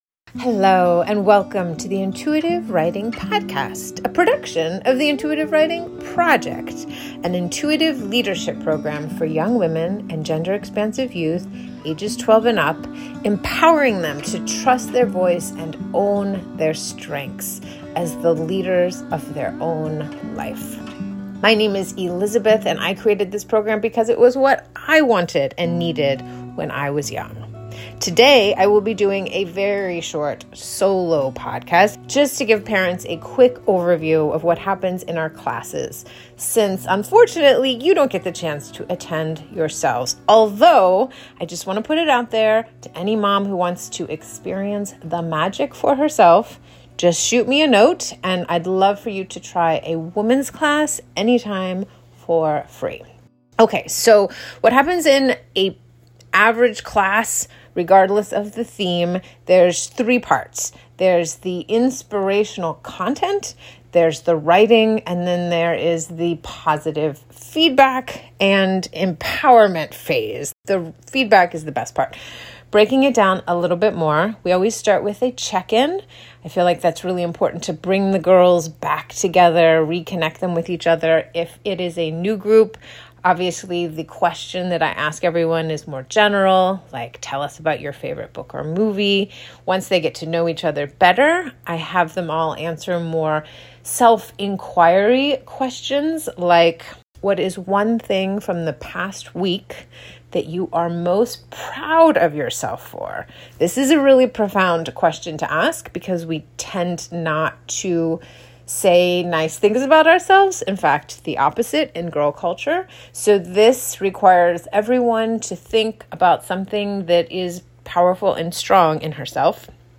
In this short, nuts-and-bolts, solo podcast